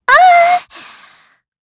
Gremory-Vox_Damage_kr_03.wav